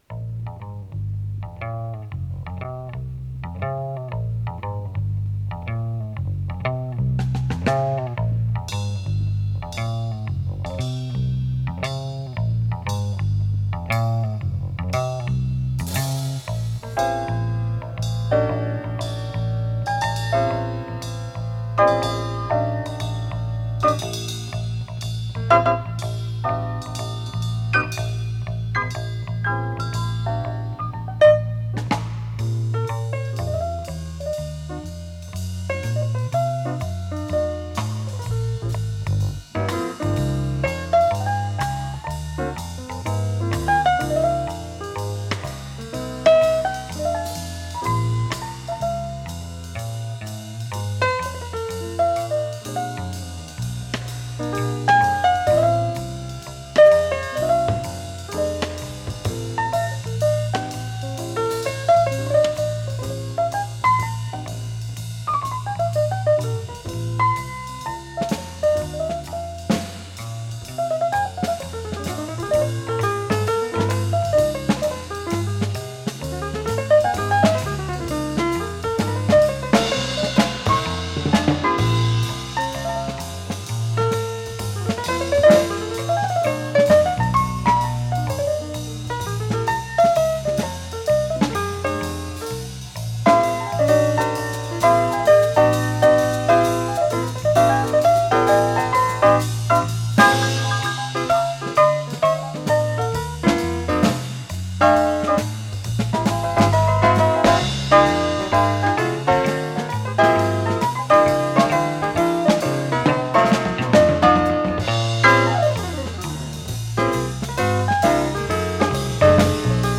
фортепиано
ударные
гитара